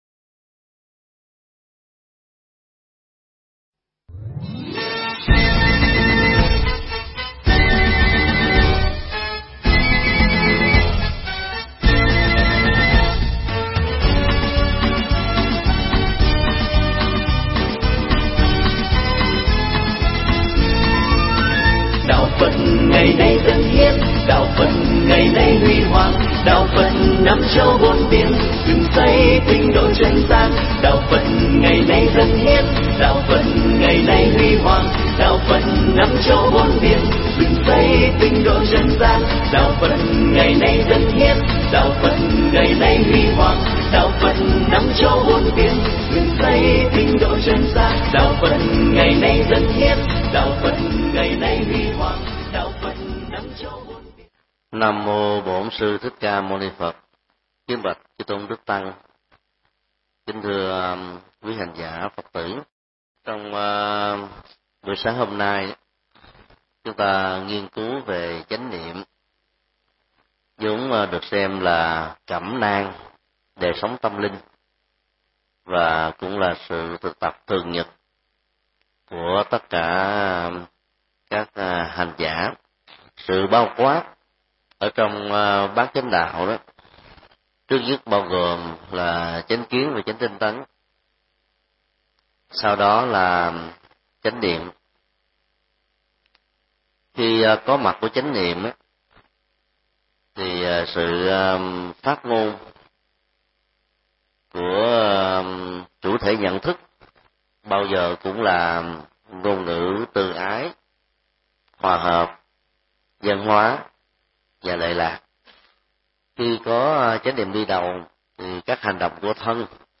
Mp3 Pháp thoại Bát Chánh Đạo 7: Chánh niệm: Nền tảng các pháp môn do thầy Thích Nhật Từ thuyết giảng tại trường hạ tịnh xá Trung Tâm ngày 14 tháng 08 năm 2009